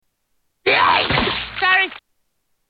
Vomits
Category: Television   Right: Personal